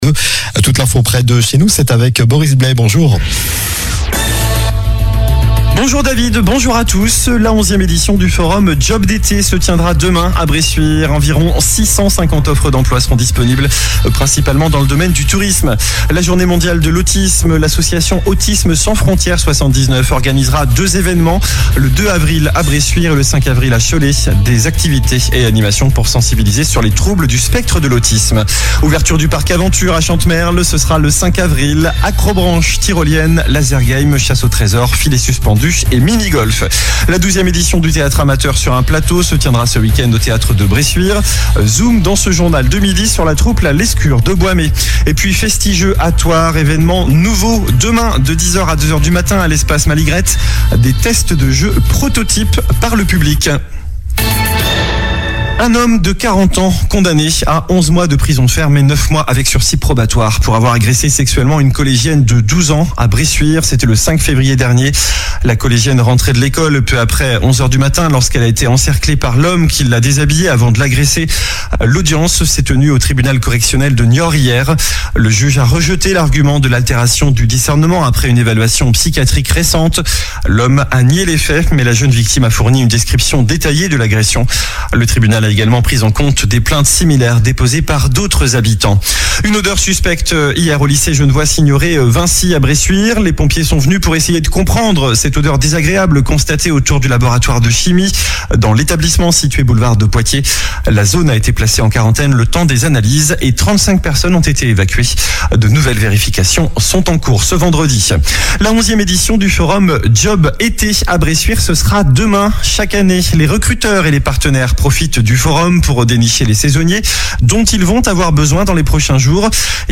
Journal du vendredi 28 mars (midi)